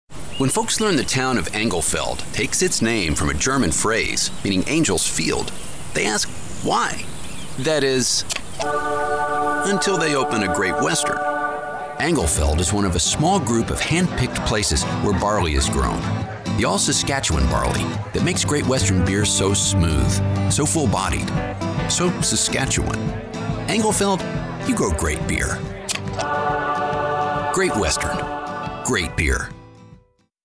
Great Western Beer Commercial
Grown in Englefeld - Saskatchewan's Great Western Beer Company Commercial.